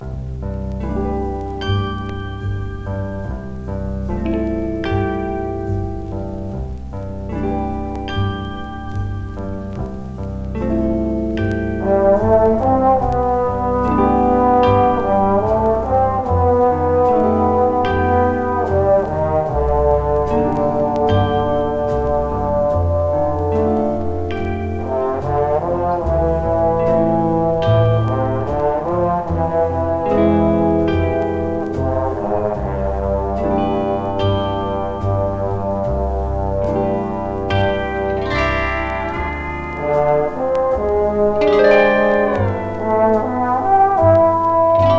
Jazz, Pop　USA　12inchレコード　33rpm　Mono